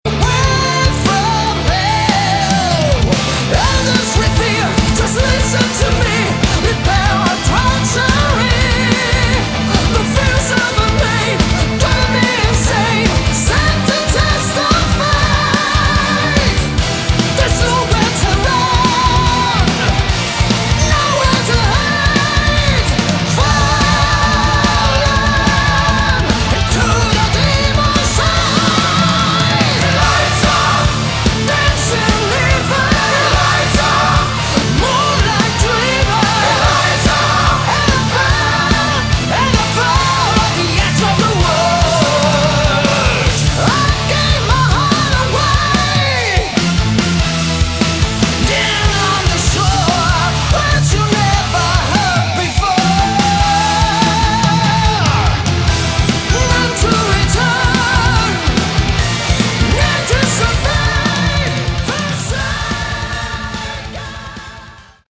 Category: Melodic Metal / Prog / Power Metal
guitars
keyboards
drums
bass
vocals
violin